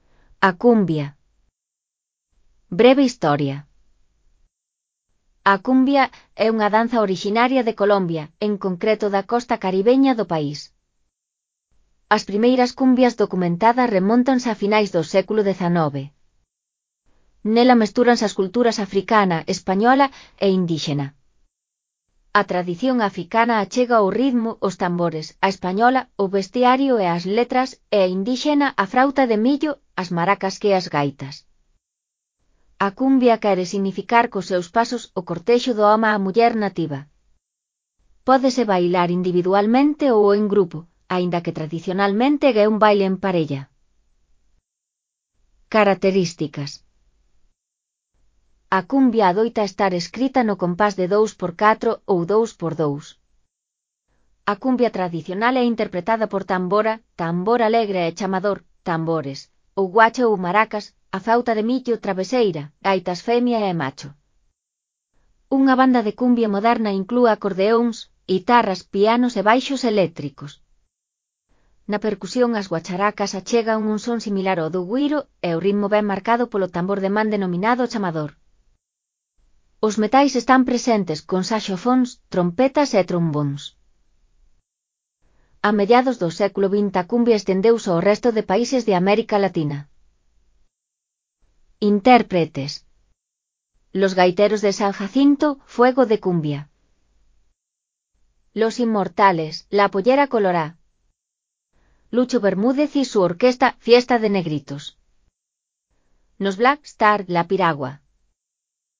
3.1. Cumbia
• Compás de 2/4 ou 2/2.
• Interpretada por tambores, guache ou maracas, frauta de millo, gaitas femia e macho.
AUD_MUD_6PRI_REA02_A_CUMBIA_V01.mp3